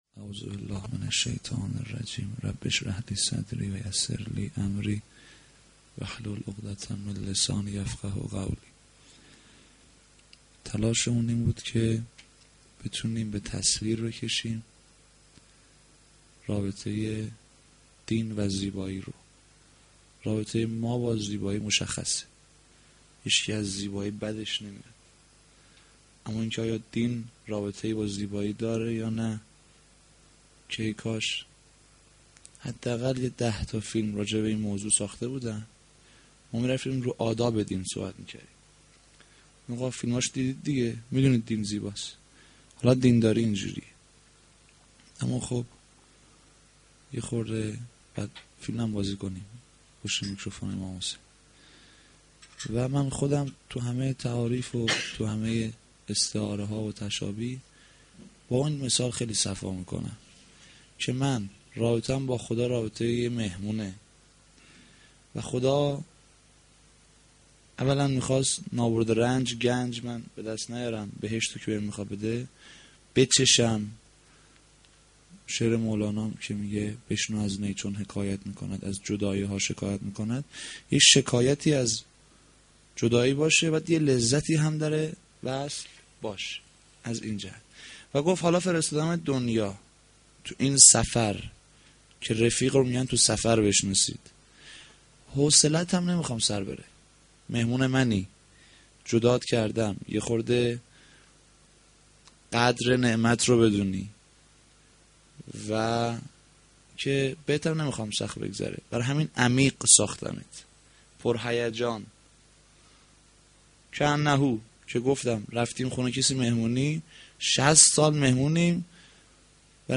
sh-8-moharram-92-sokhanrani.mp3